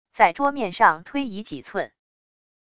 在桌面上推移几寸wav音频